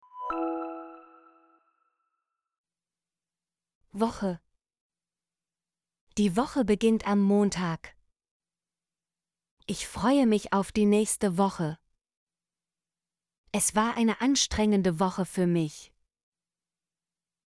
woche - Example Sentences & Pronunciation, German Frequency List